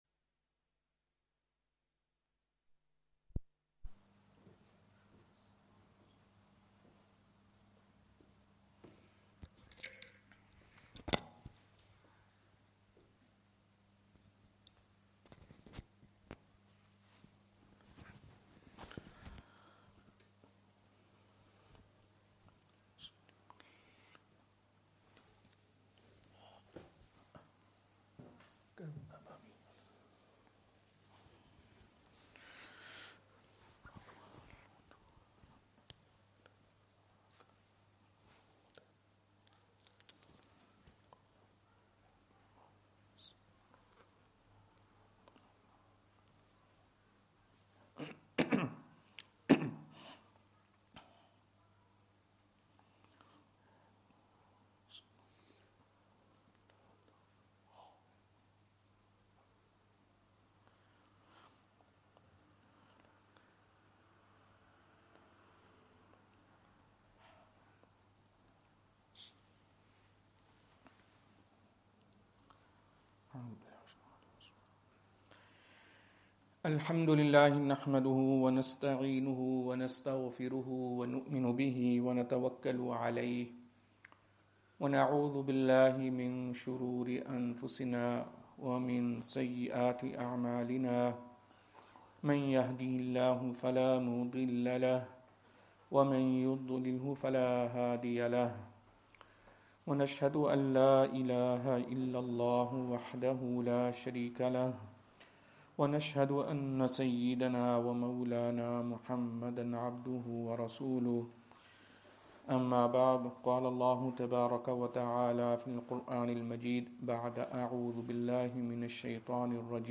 Jumah
Madni Masjid, Langside Road, Glasgow